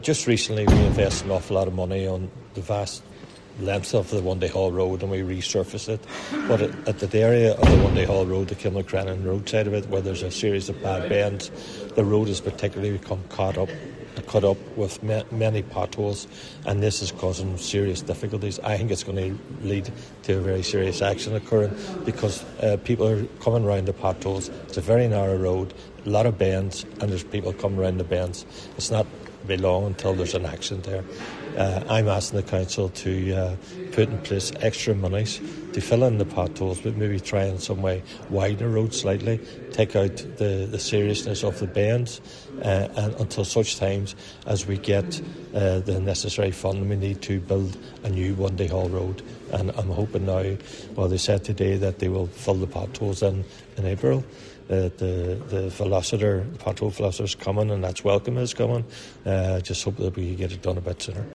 The council say that maintenance work will begin during April, but Cllr McMonagle says this should happen much sooner: